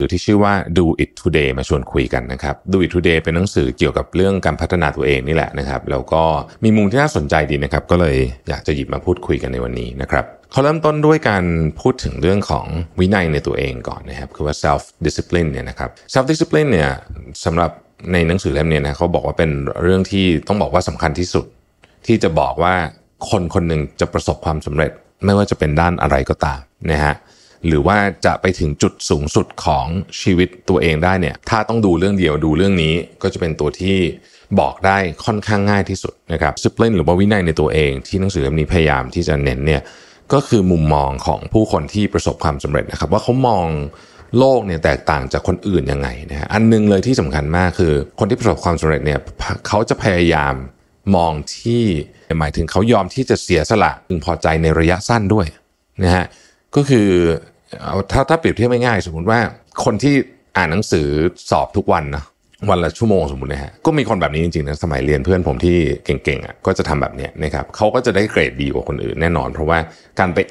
Thai_Male_Sourse.wav